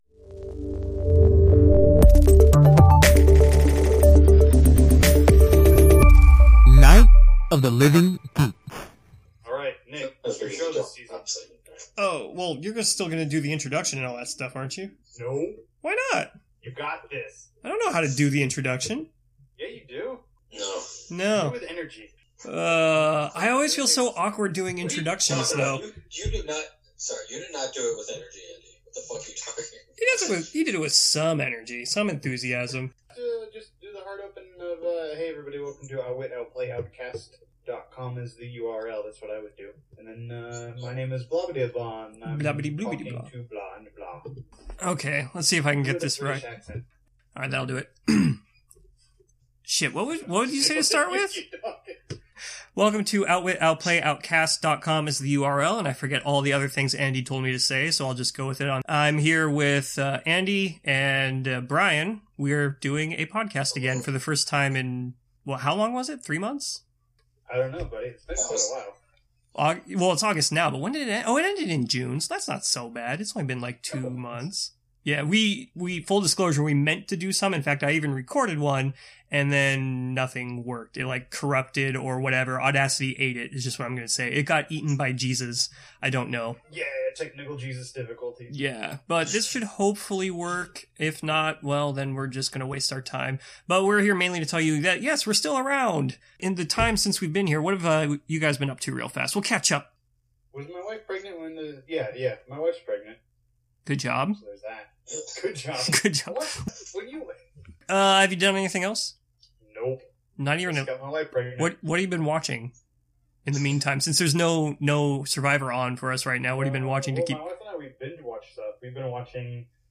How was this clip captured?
As a fun little game, see if you can figure out who did the recording based on the sound levels!